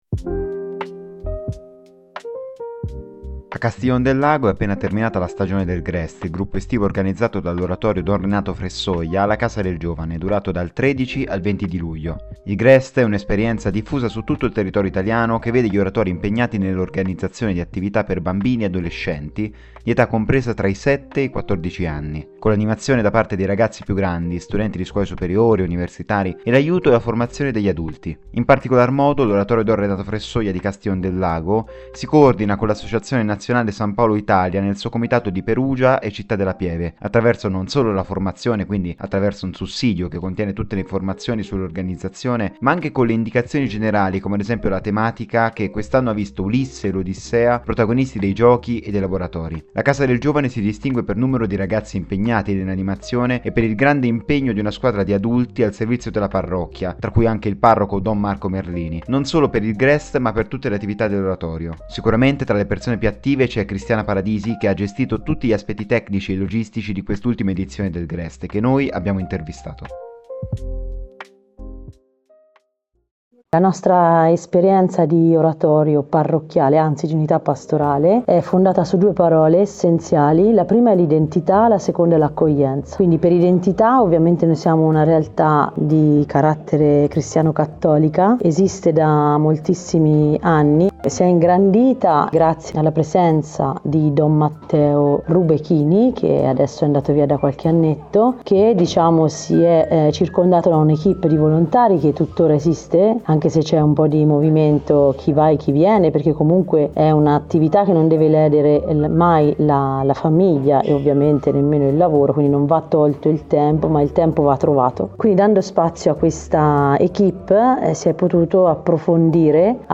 intervistato